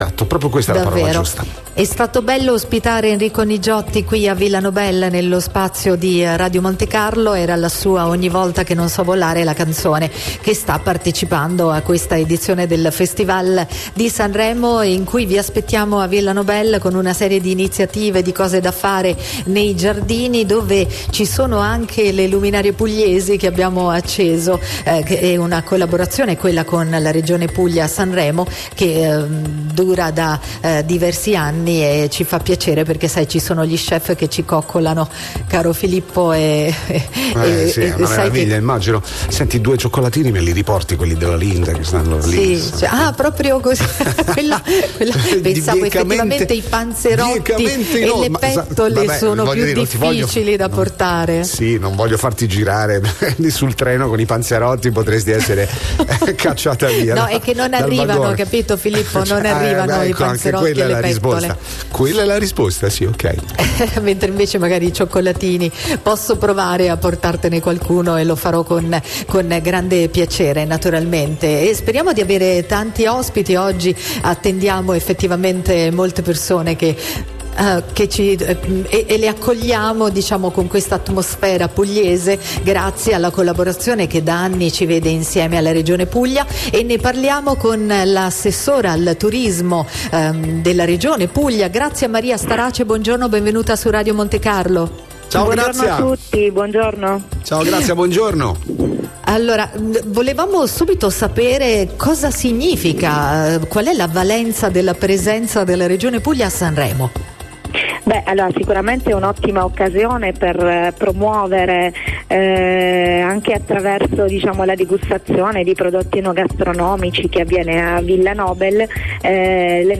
Tra gli ospiti della nostra settimana a Extra Festival, anche Graziamaria Starace, Assessore al Turismo e Promozione della Regione Puglia.
Pur non potendo raggiungere di persona Villa Nobel, cuore del nostro radio-tv hub sanremese, l’assessore è intervenuta ai microfoni di Radio Monte Carlo raccontando con entusiasmo e orgoglio il grande lavoro di promozione che la Puglia ha portato a Sanremo durante la settimana del Festival.